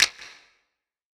kits/OZ/Percs/Snap (Popmusic).wav at main
Snap (Popmusic).wav